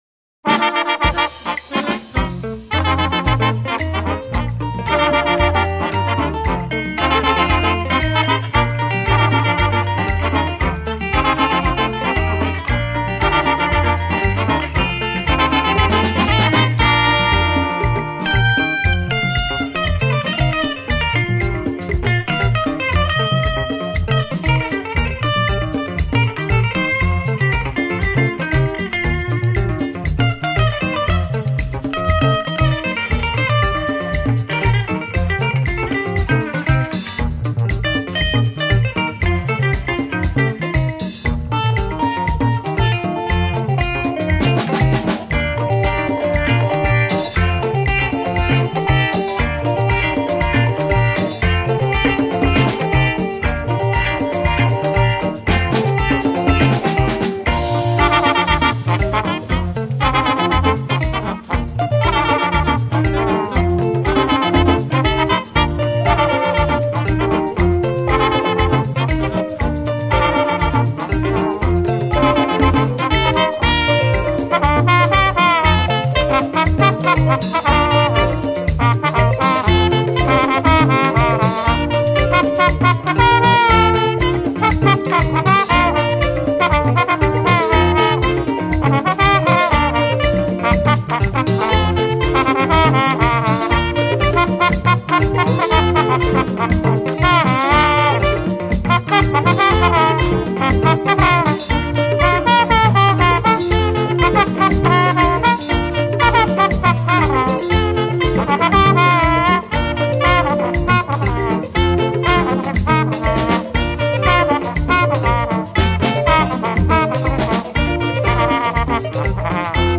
September 10, 2024 admin Highlife Music, Music 0
highlife single